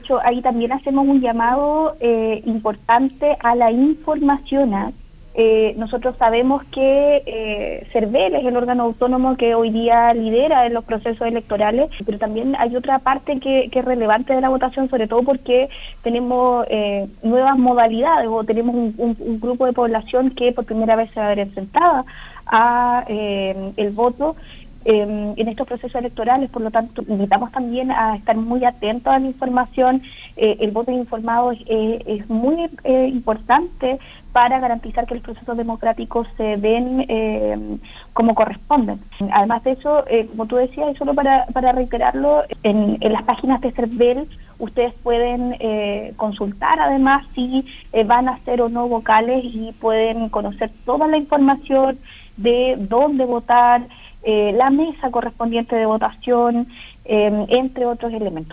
La Seremi de Gobierno, enfatizó que es muy importante revisar la información actualizada, pues existe un número considerable de electores que por primera vez deben sufragar en los próximos comicios.